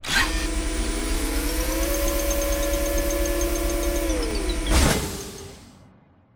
dock1.wav